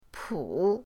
pu3.mp3